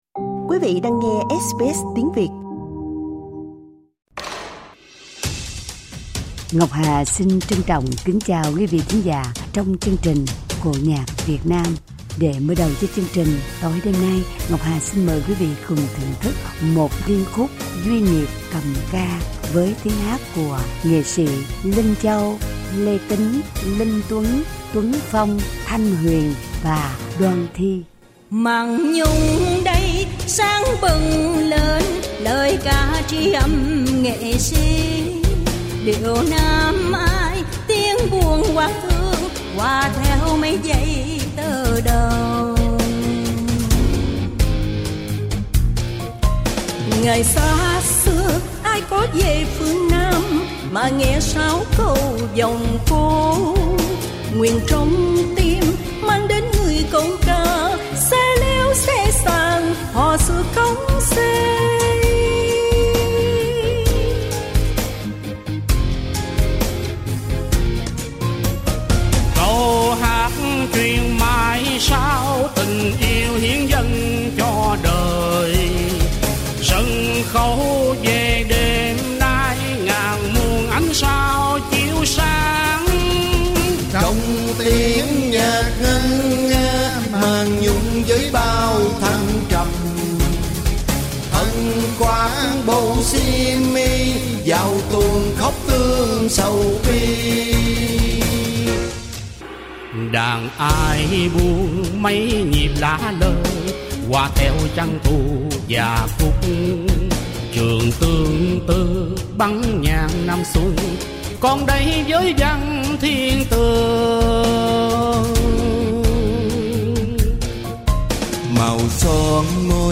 Nhân ngày Lễ giỗ tổ Cải Lương 2022, chương trình cổ nhạc 'Duyên Nghiệp- Kiếp cầm ca' do anh chị em nghệ sĩ trình bày.
Lễ giỗ tổ Cải Lương 2022 Source